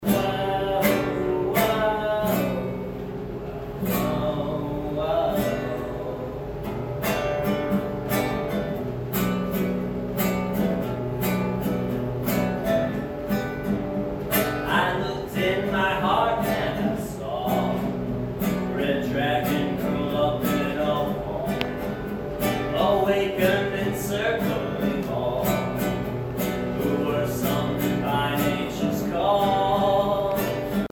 Emporia’s 10 West Grill and Dive Bar was full of folk and cheer Sunday as it hosted the very first Farmette conference.
farmette-performance.mp3